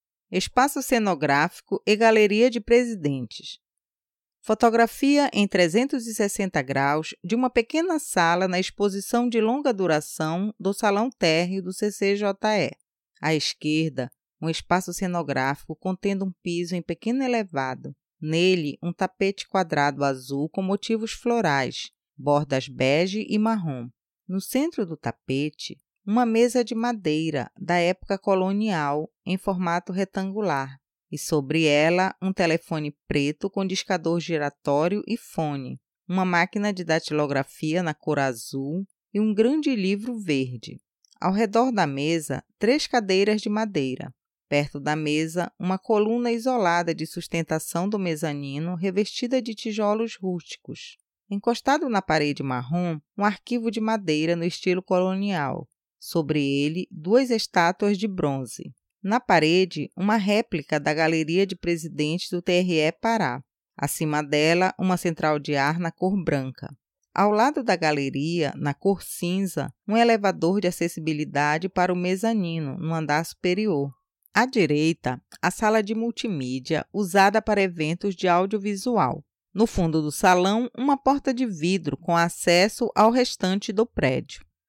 Espaço Cenográfico e Galeria dos Presidentes audiodescrição